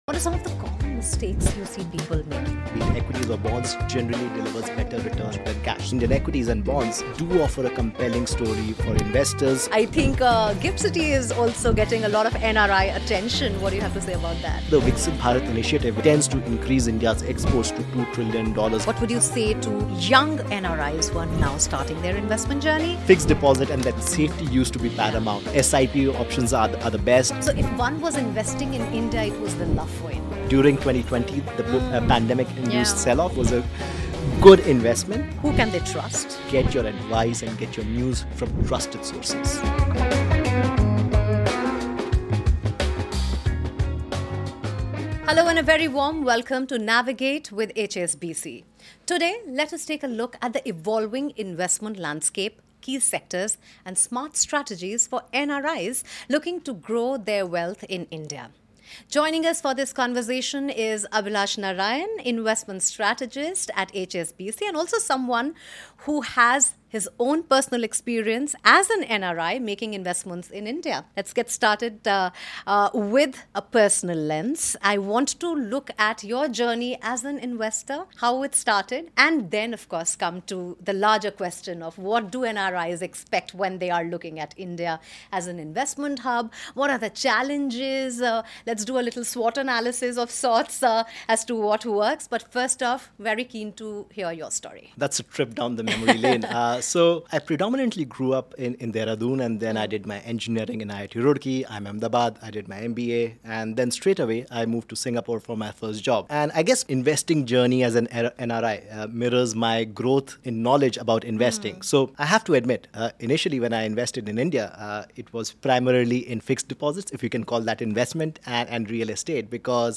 Hear the full conversation on our podcast Navigate with HSBC – NRI Banking, in association with CNBC-TV18.